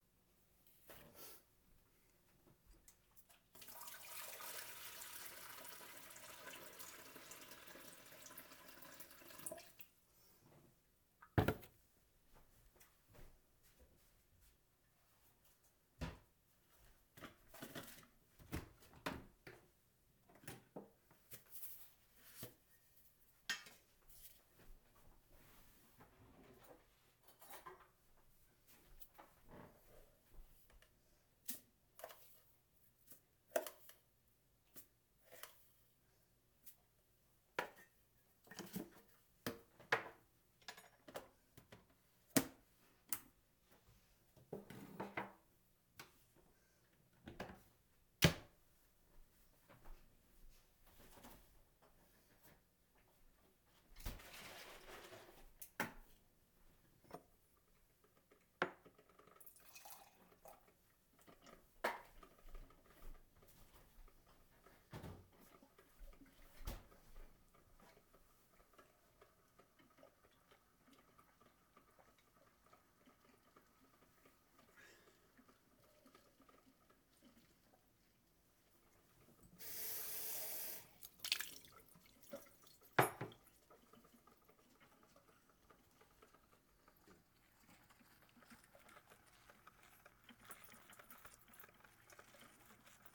Morning sounds
First_morning_sounds.mp3